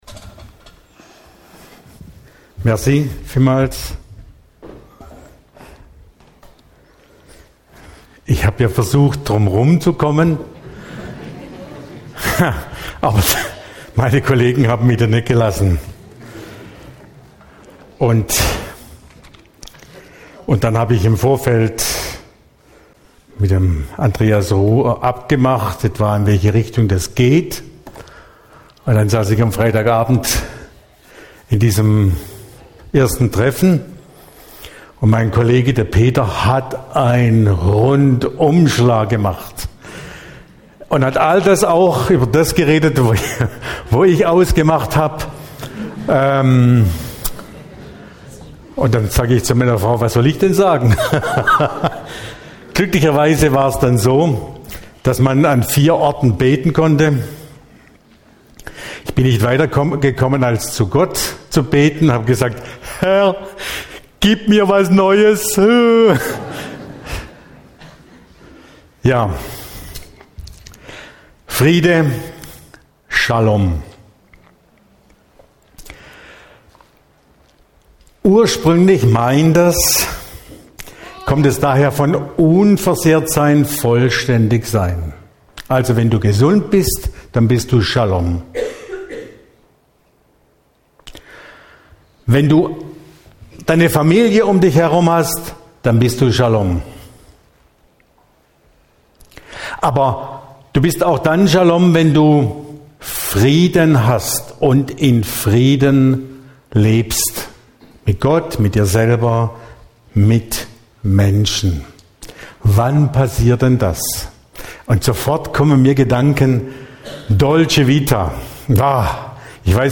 Allianz Gottesdienst: „Shalom“ › Lenzchile